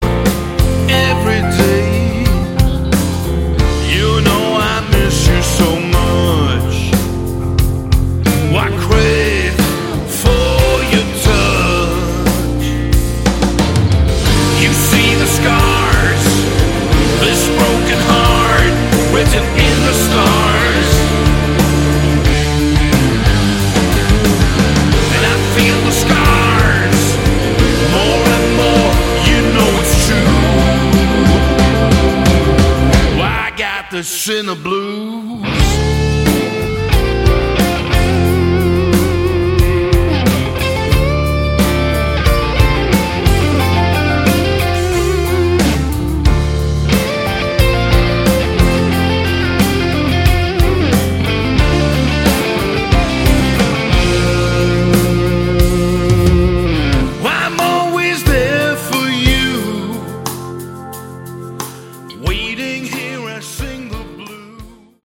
Category: Melodic Metal
vocals, bass
guitar
drums